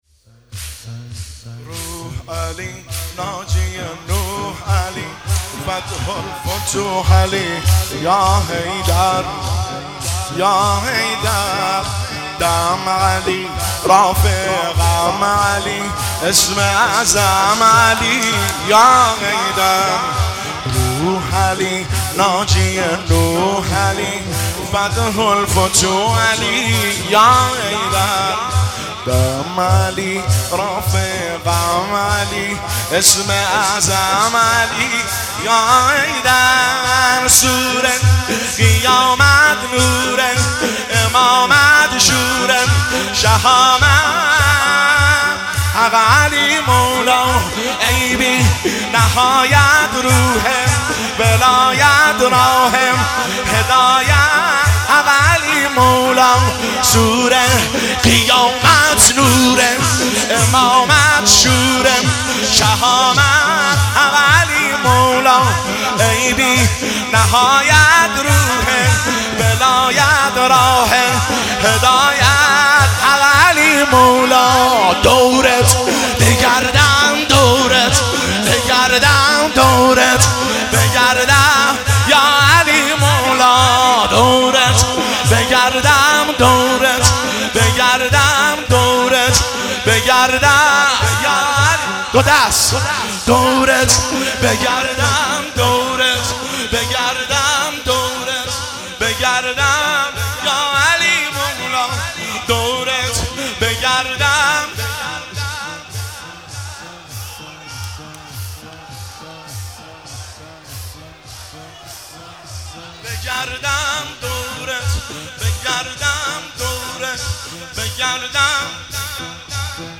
مداحی تک طوفانی